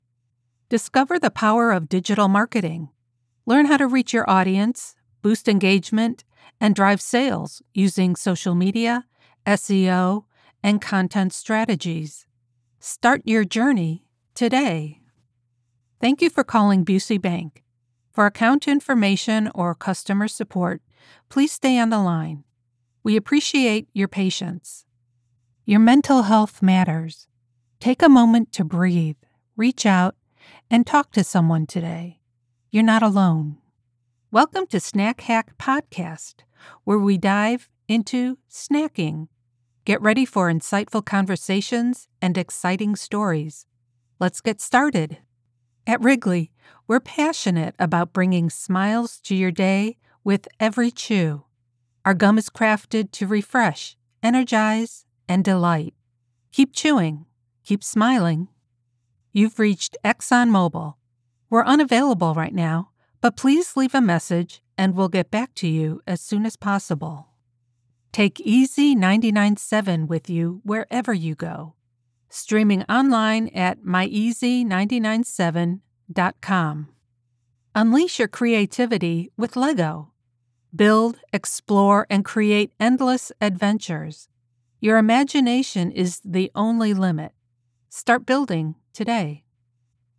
Professional Female Voice Over Talent
Business Uses Demo
Let’s work together to bring your words to life with my conversational, authoritative and articulate voice.